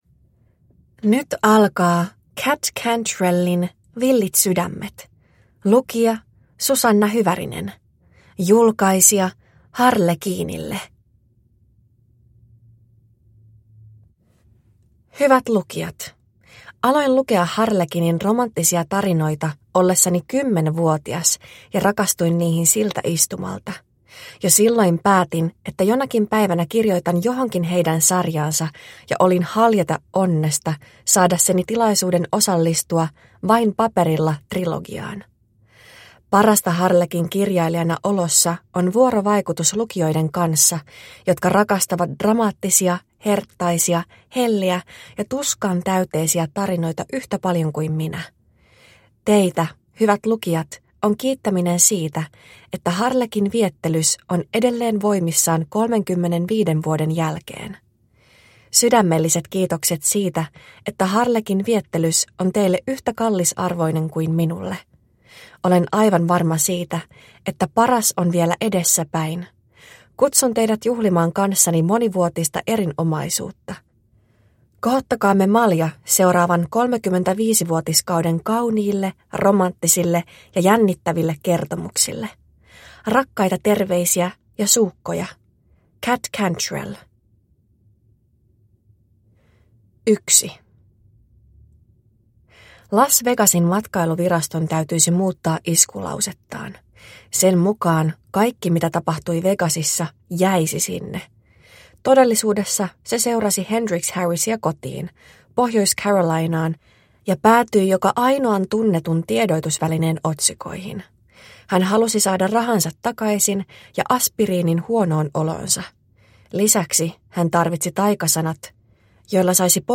Villit sydämet – Ljudbok